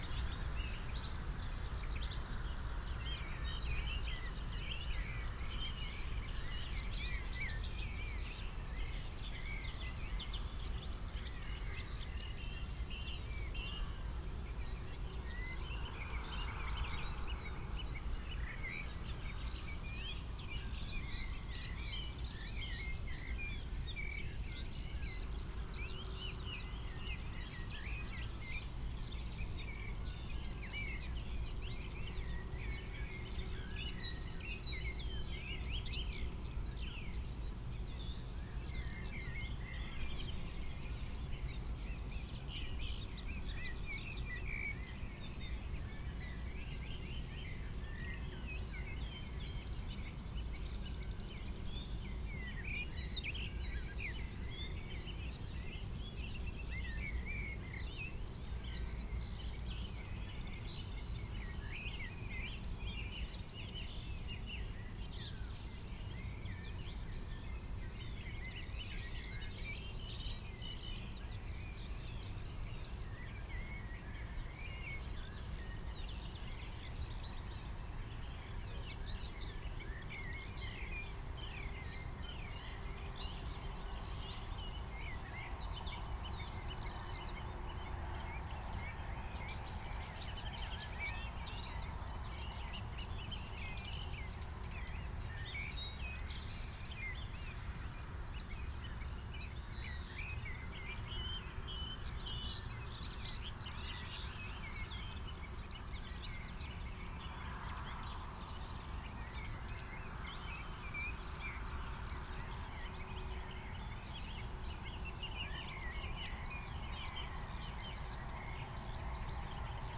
Gallus gallus domesticus
Corvus corone
Emberiza citrinella
Turdus philomelos
Fringilla coelebs
Sylvia communis
Sylvia atricapilla
Cyanistes caeruleus
Turdus merula
Alauda arvensis
Erithacus rubecula
Coccothraustes coccothraustes
Dendrocopos major
Asio otus